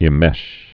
(ĭm-mĕsh)